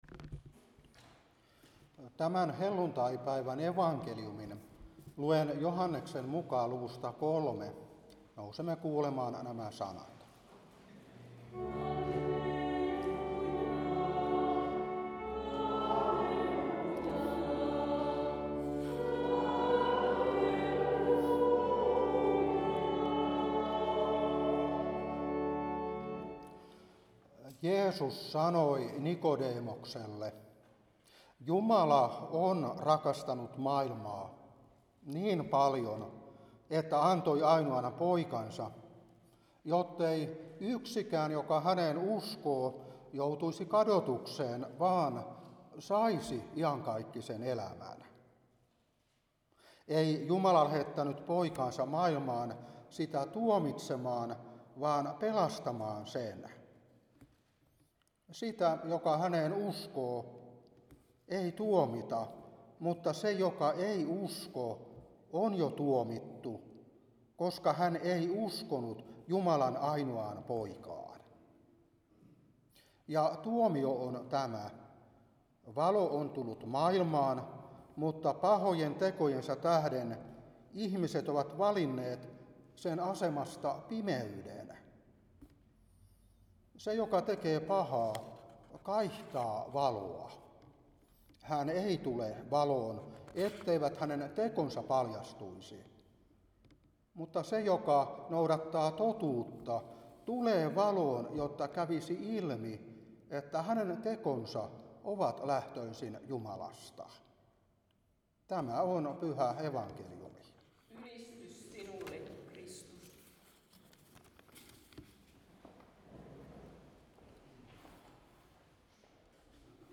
Saarna 2024-5.